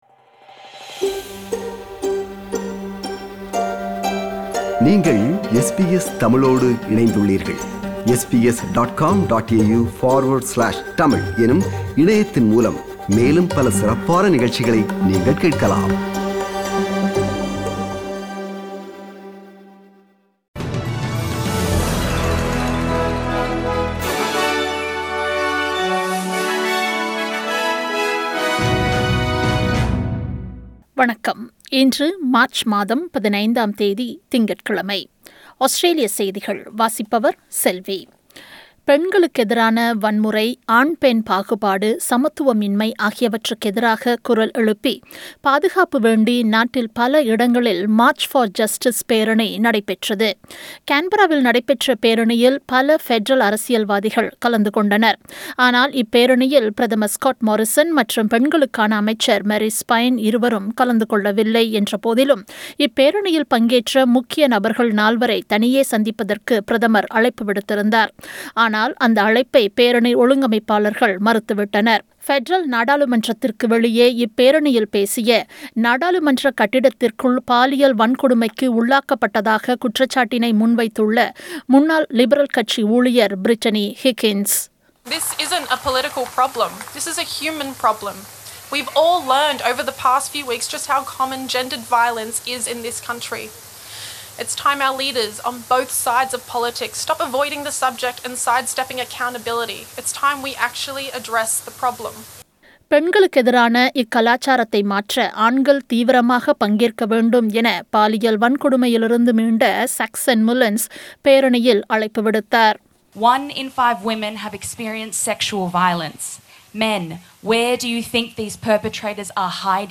Australian news bulletin for Monday 15 March 2021.